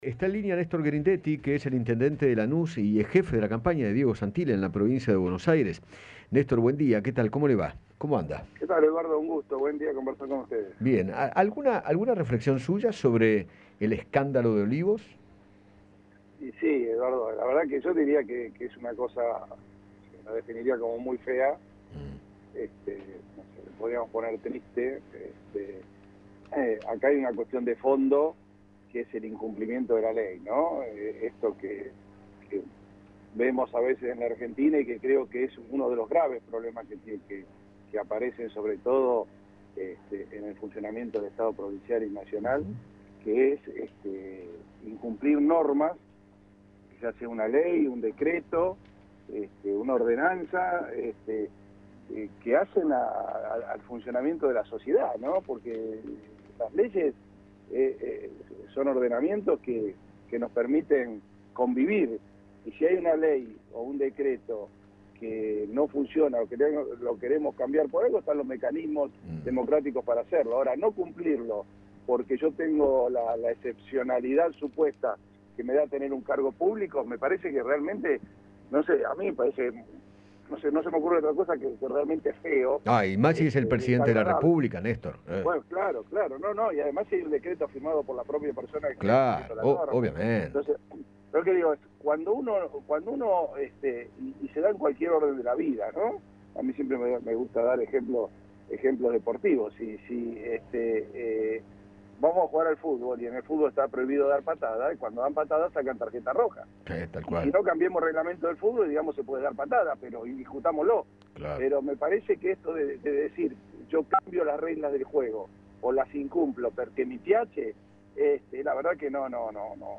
Néstor Grindetti, intendente de Lanús conversó con Eduardo Feinmann sobre la campaña electoral de Diego Santilli en la Provincia de Buenos Aires y se refirió al escándalo por las visitas en Olivos.